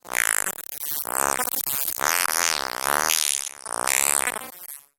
Accidentally ran time-stretch with almost everything set to maximum.